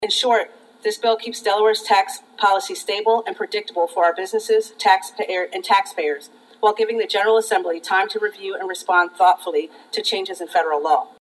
The sponsor of the Bill–House Majority Leader and State Representative Kerri Evelyn Harris discussed the reason for the bill…
Extraordinary-Session-1.mp3